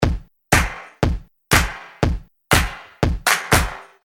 踢帽子
描述：踢球的时候，要有气势，要打开帽子
标签： 120 bpm House Loops Drum Loops 344.58 KB wav Key : Unknown
声道单声道